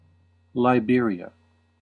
1. ^ /lˈbɪəriə/
En-us-Liberia.ogg.mp3